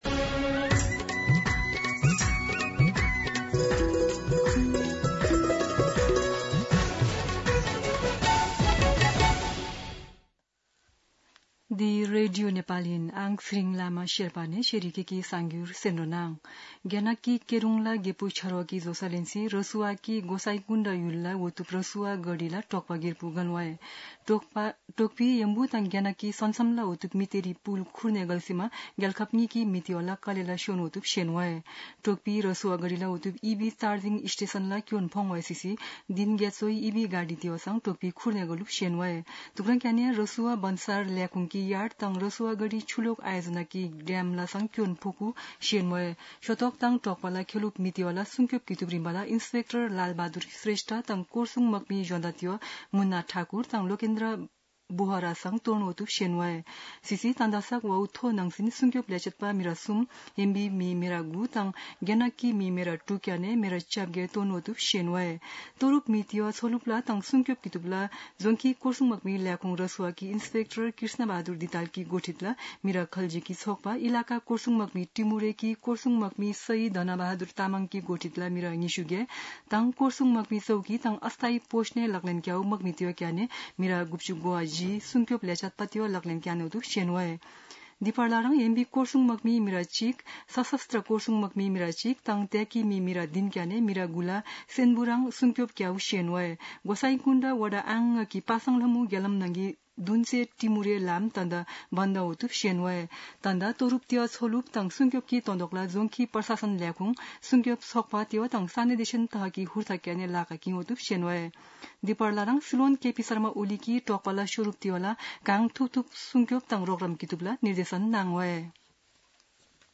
शेर्पा भाषाको समाचार : २४ असार , २०८२
Sherpa-News-24.mp3